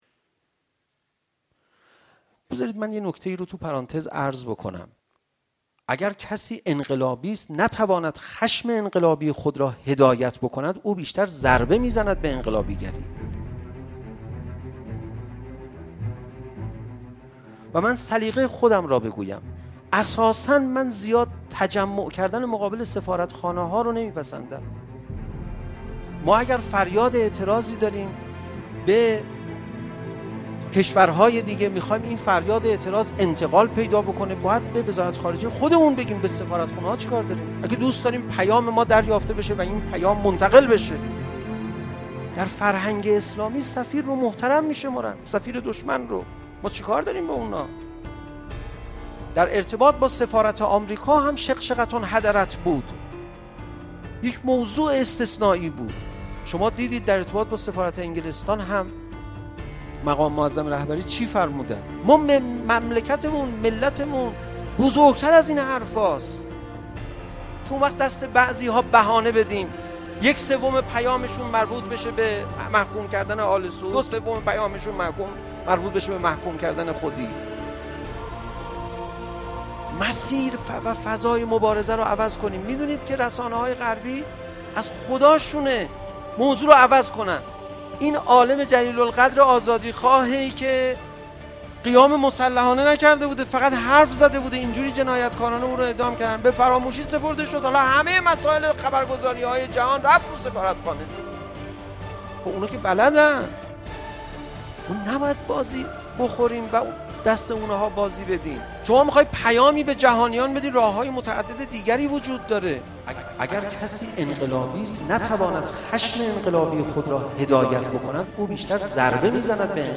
تولید: بیان معنوی مدت زمان: 02:10 دقیقه منبع: دانشگاه امام صادق(ع)/ مراسم بزرگداشت آیت الله نمر و شهدای مدافع حریم متاسفانه مرورگر شما، قابیلت پخش فایل های صوتی تصویری را در قالب HTML5 دارا نمی باشد.